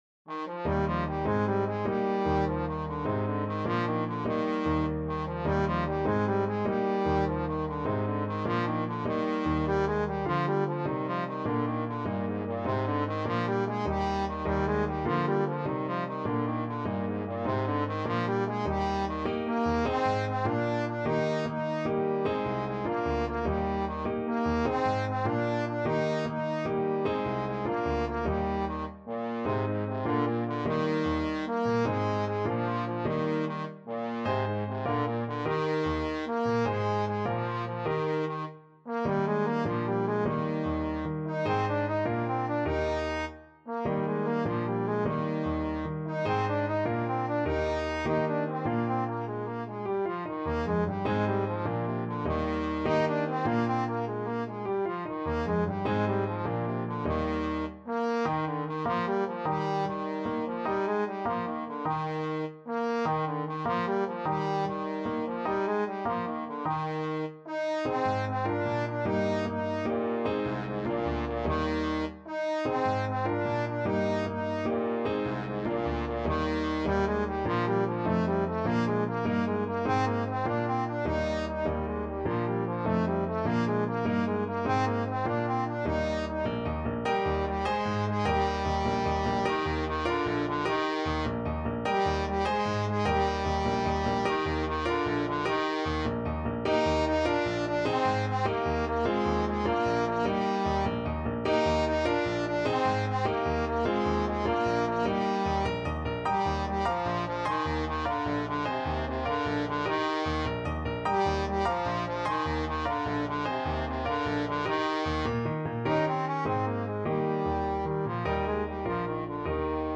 6/8 (View more 6/8 Music)
Classical (View more Classical Trombone Music)